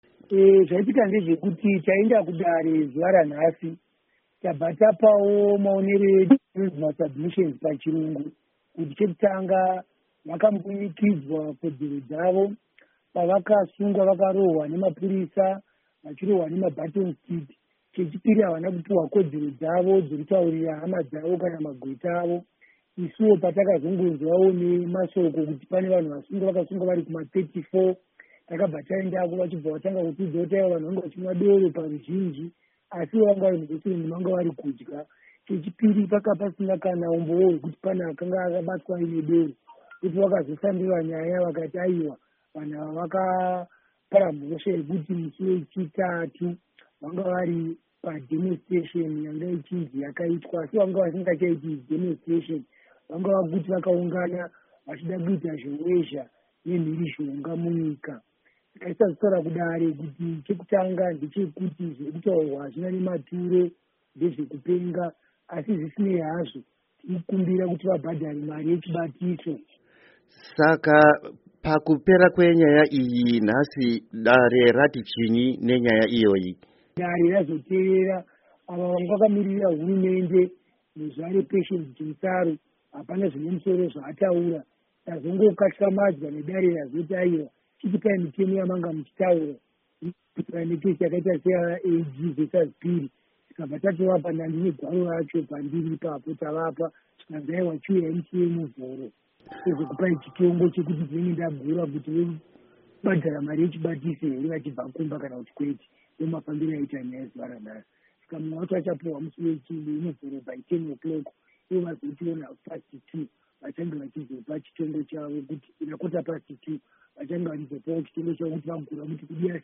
Hurukuro naVaInnocent Gonese